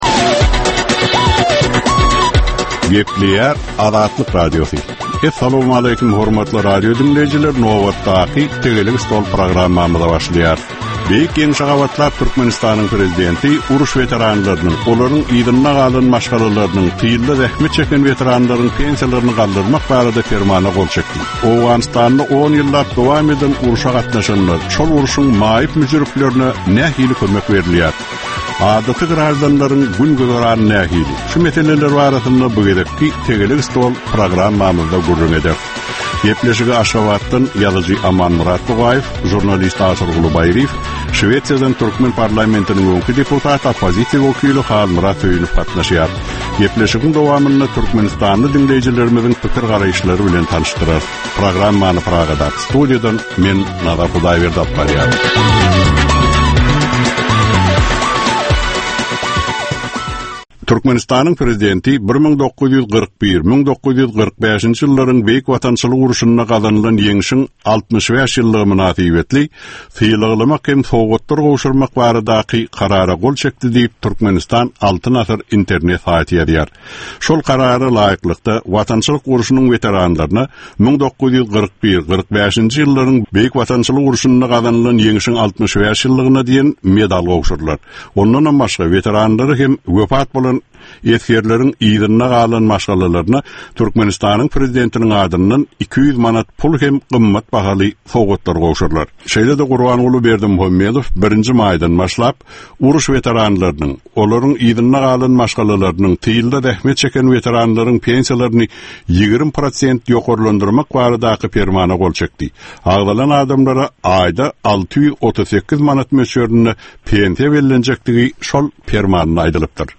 Jemgyýetçilik durmuşynda bolan ýa-da bolup duran soňky möhum wakalara ýa-da problemalara bagyşlanylyp taýýarlanylýan ýörite diskussiýa. 30 minutlyk bu gepleşikde syýasatçylar, analitikler we synçylar anyk meseleler boýunça öz garaýyşlaryny we tekliplerini orta atýarlar.